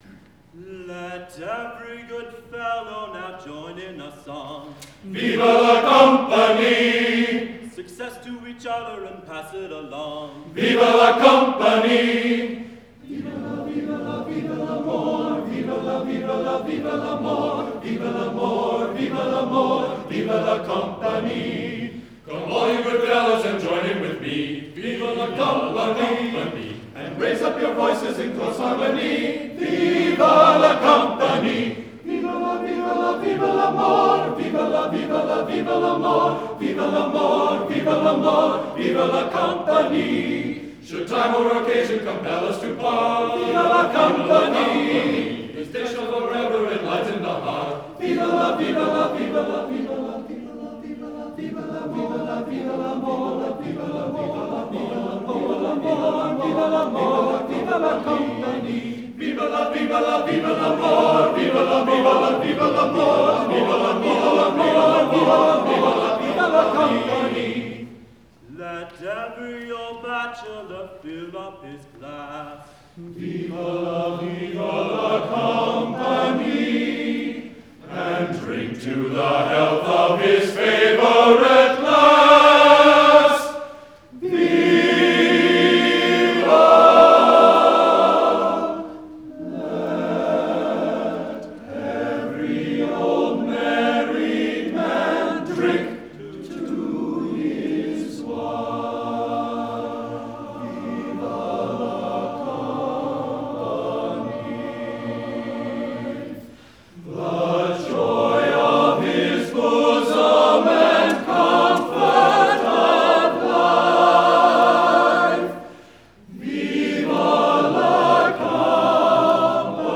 Fall 2004 — Minnesota Valley Men's Chorale